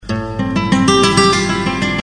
guitar nș 149
guitar149.mp3